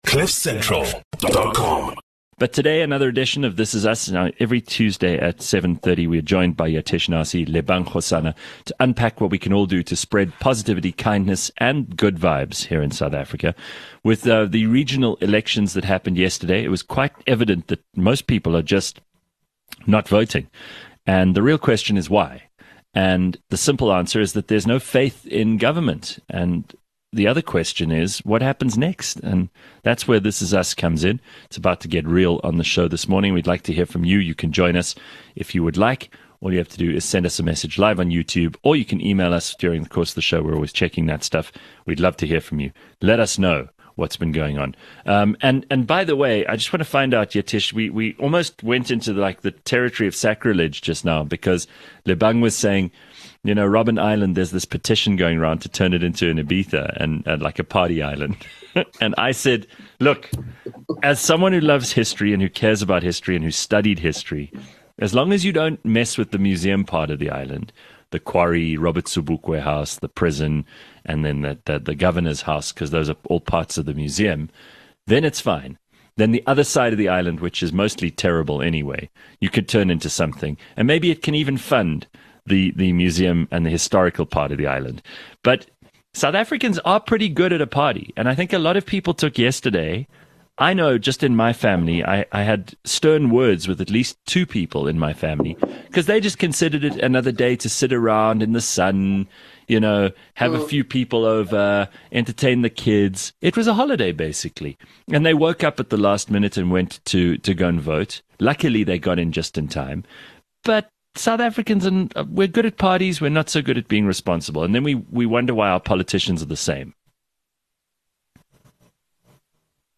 This week the team have a candid conversation about the voter turnout, which was just under 30% - the lowest it’s been in 27 years! They have a look at the possible causes, the numbers, and discuss solutions.